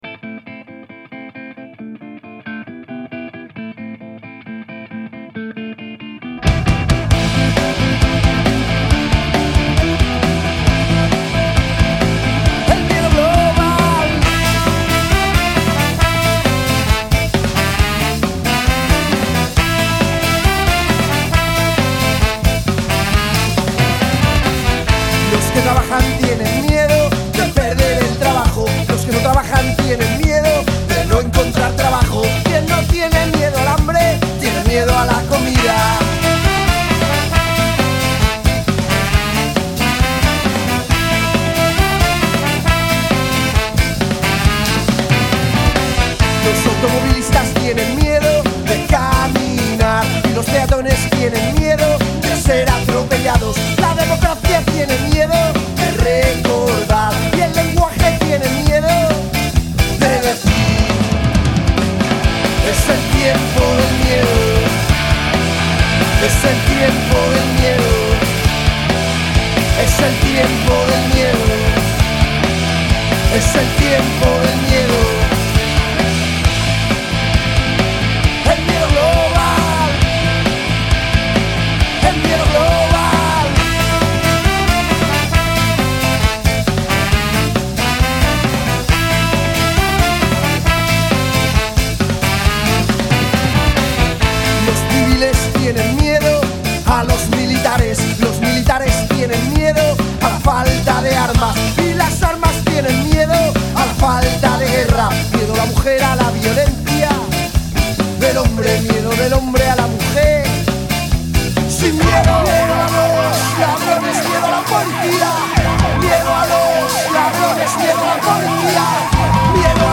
Canción, poema